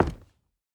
Plastic_003.wav